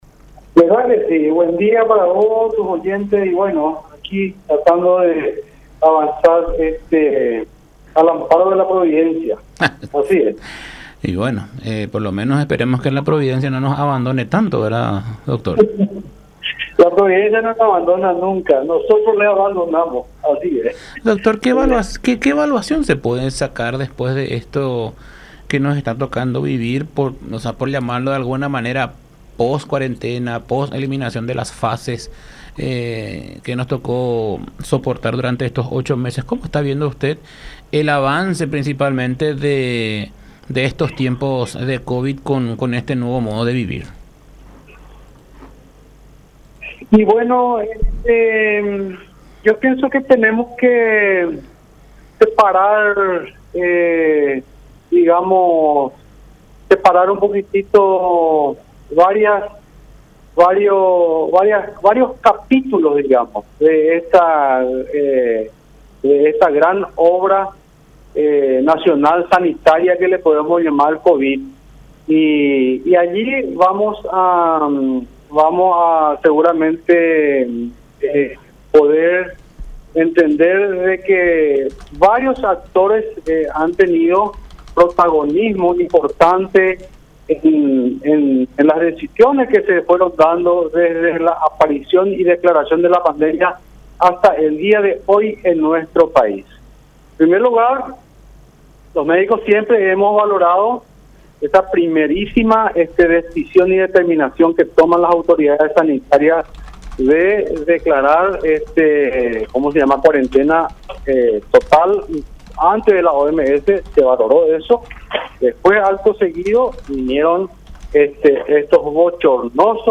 en contacto con La Unión R800 AM.